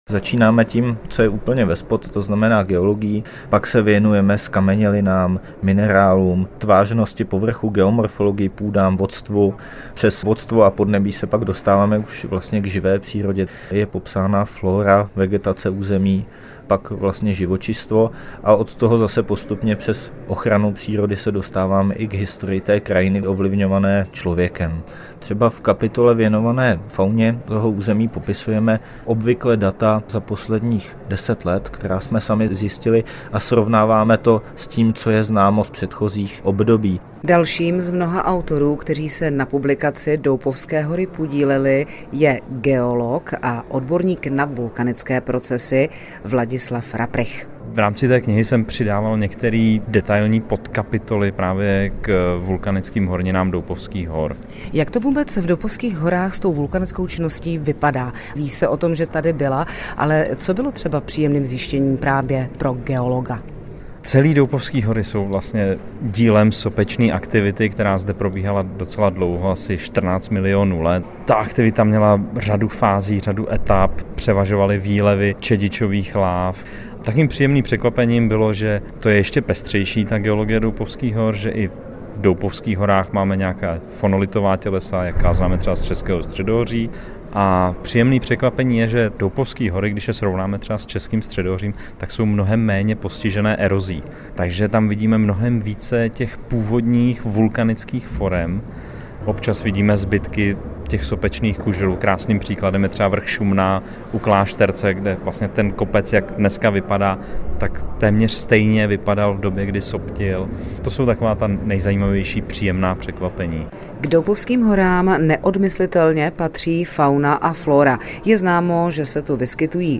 Rozhovor
nahráno ve studiu ČRo Plzeň